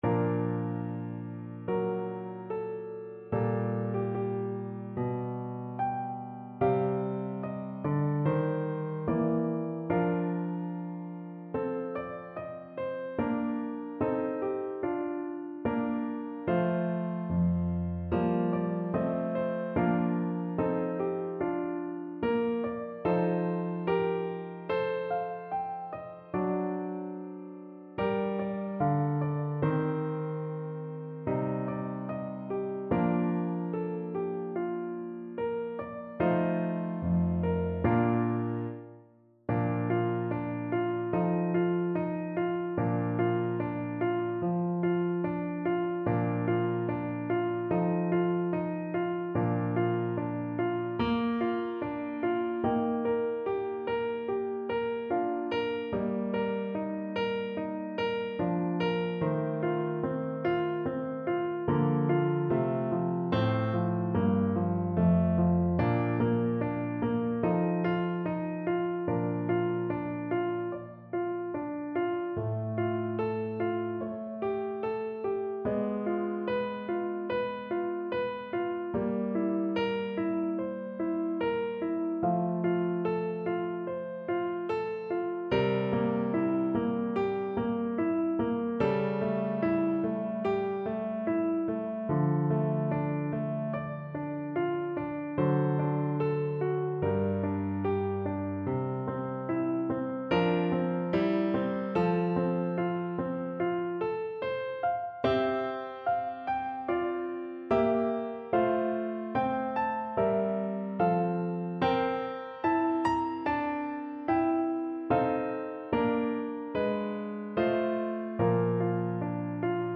Poco lento =100
4/4 (View more 4/4 Music)
A5-G6
Classical (View more Classical Clarinet Music)